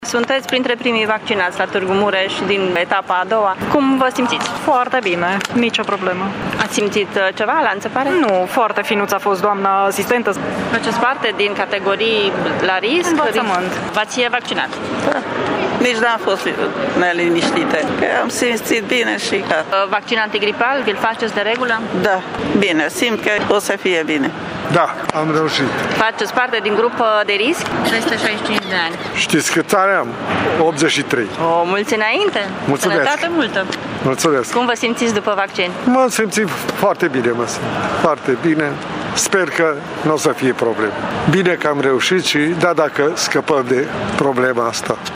Târgumureșenii care s-au vaccinat în primele ore ale zilei spun că se simt perfect și speră ca pandemia să se încheie curând: